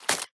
Sfx_creature_babypenguin_hop_01.ogg